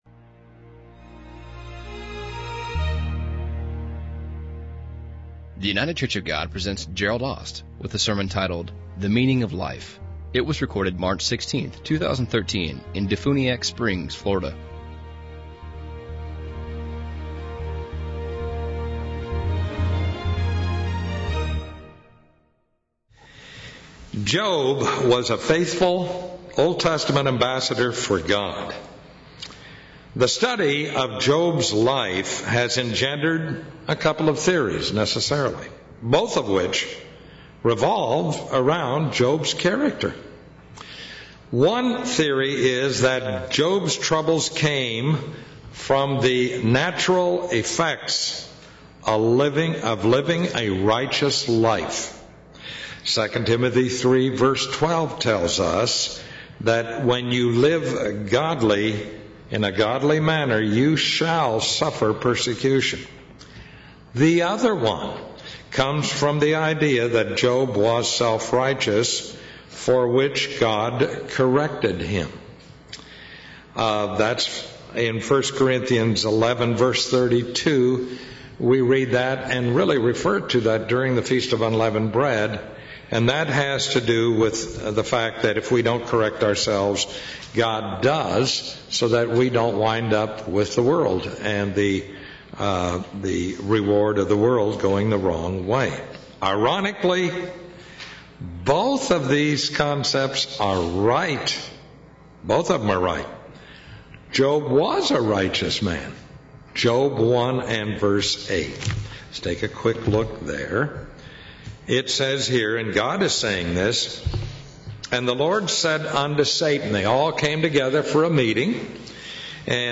In this sermon we will explore the meaning of life. Why we need to know the meaning of this life, and how it exists, how it transpires and how it progresses.